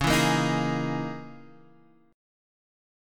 C#m6add9 chord